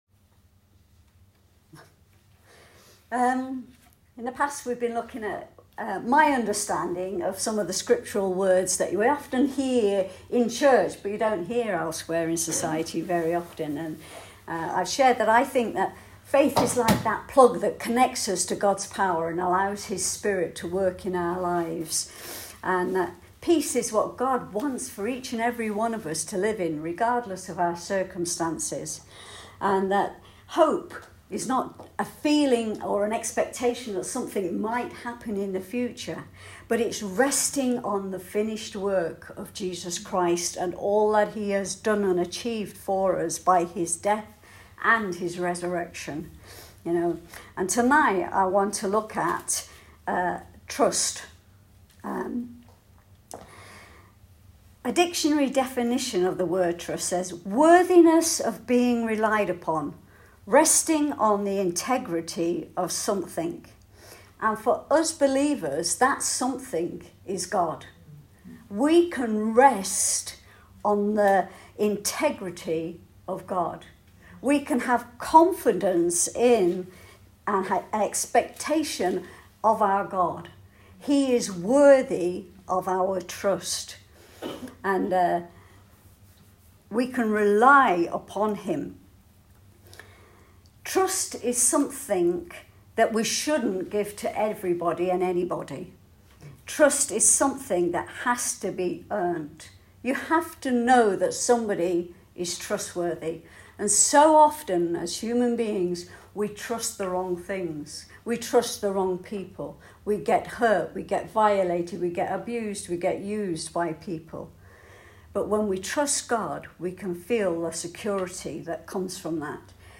Ladies message